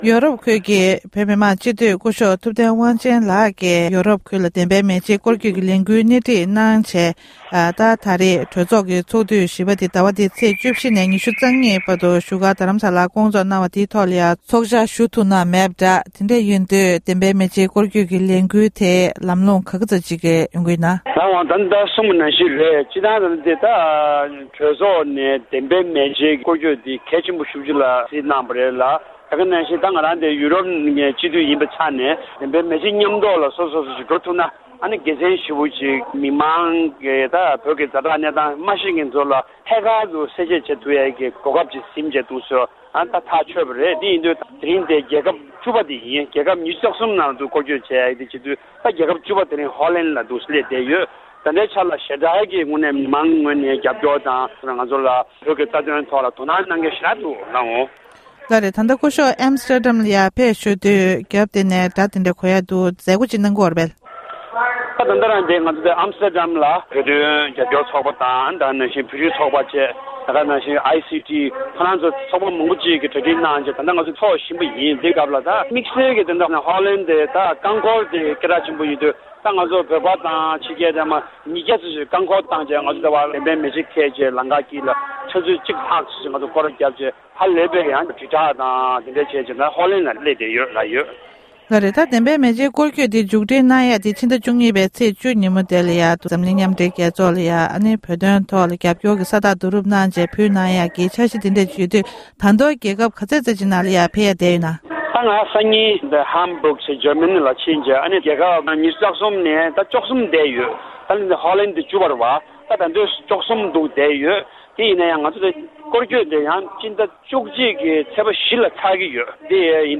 སྤྱི་འཐུས་སྐུ་ཞབས་ཐུབ་བསྟན་དབང་ཆེན།
སྒྲ་ལྡན་གསར་འགྱུར། སྒྲ་ཕབ་ལེན།